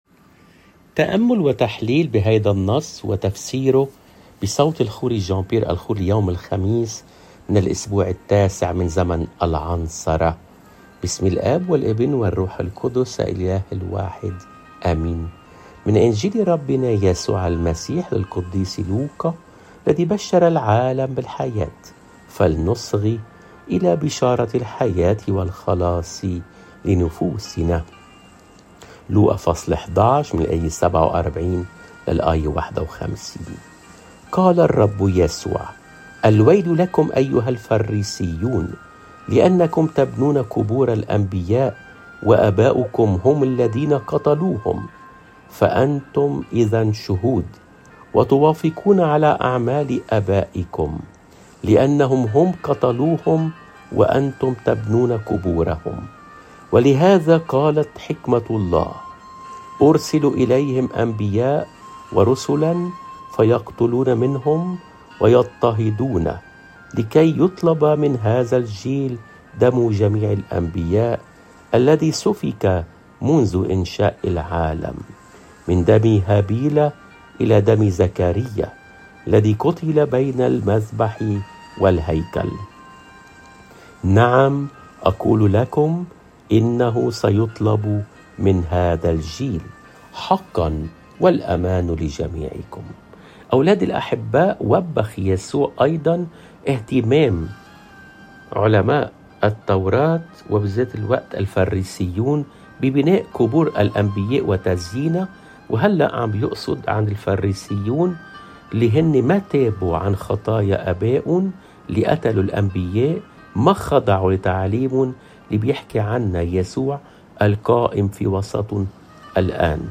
الإنجيل